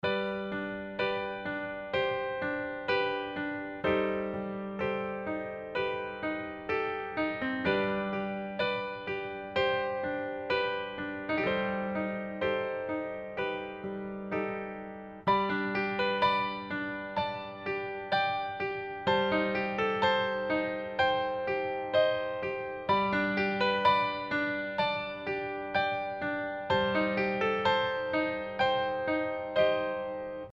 Wechselt man zum C7 Club, so werden neue Samples geladen.